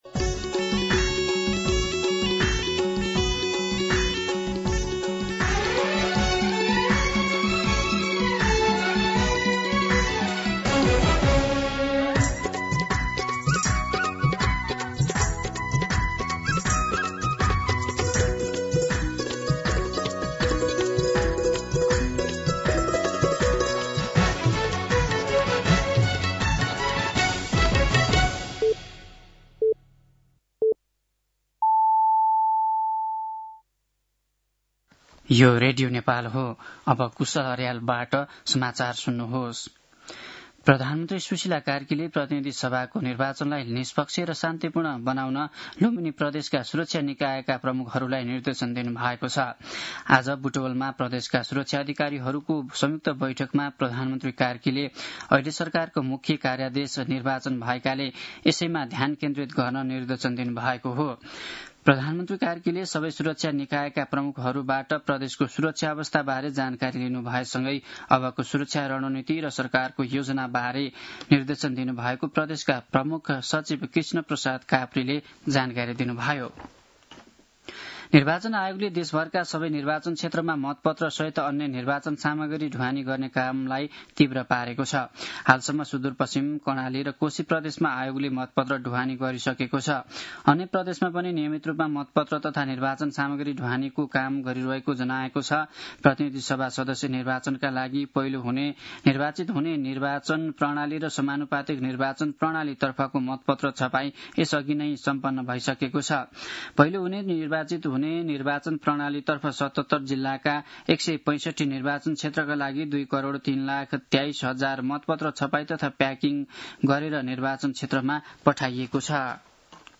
दिउँसो १ बजेको नेपाली समाचार : ९ फागुन , २०८२
1-pm-Nepali-News-4.mp3